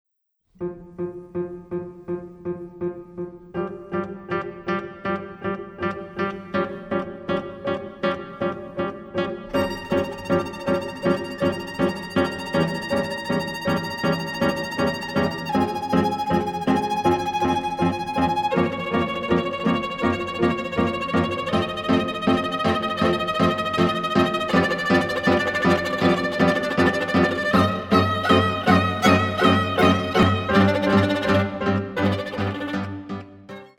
Geige
Klavier
Schlagwerker